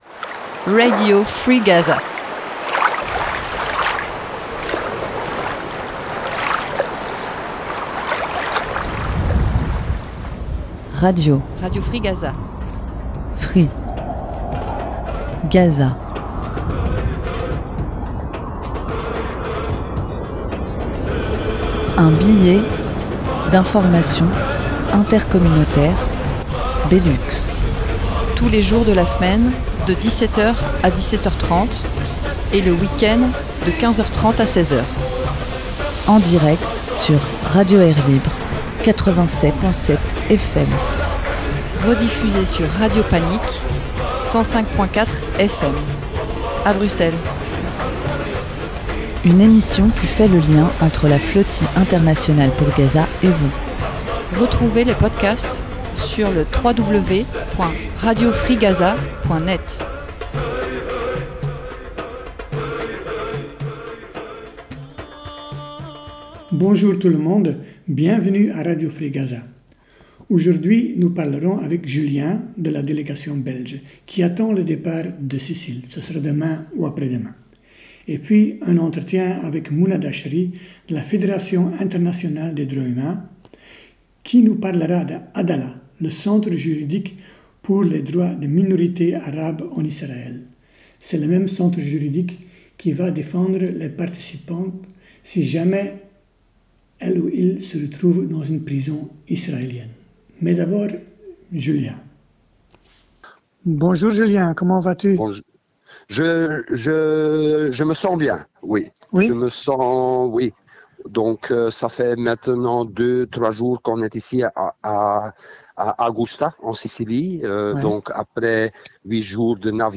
Suivi d’un entretien